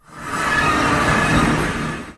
portal.wav